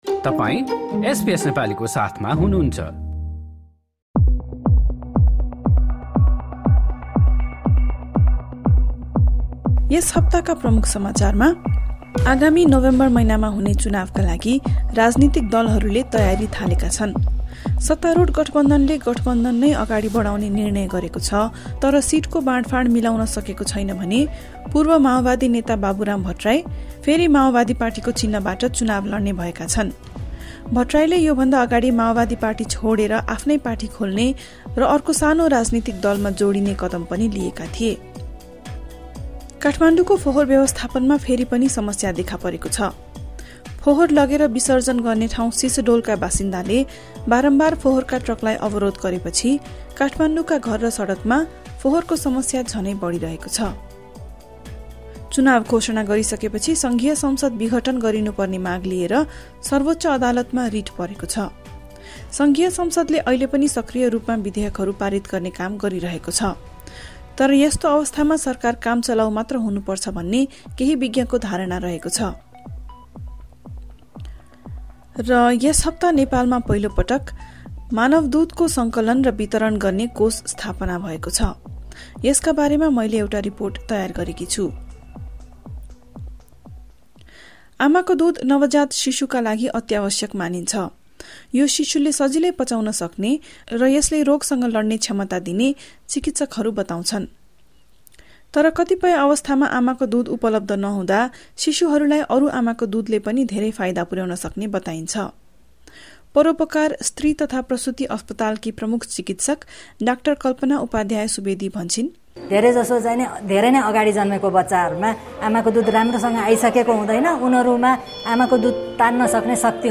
शुक्रवार अमृत कोष नाम दिइएको मानव दूध सङ्कलन र वितरण कोषको राष्ट्रपति विद्यादेवी भण्डारीले उद्घाटन गरेकी छिन्। यस लगायत पछिल्ला सात दिनका प्रमुख समाचार सुन्नुहोस्।